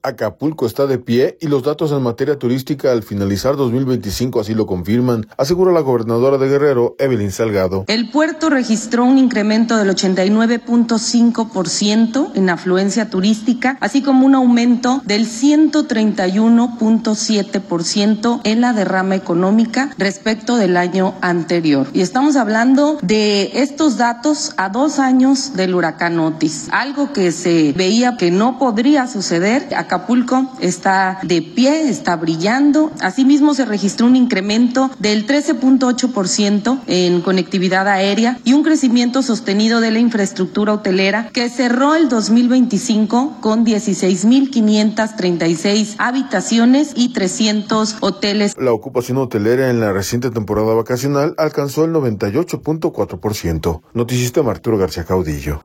Acapulco está de pie y los datos en materia turística al finalizar 2025 así lo confirman, asegura la gobernadora de Guerrero, Evelyn Salgado.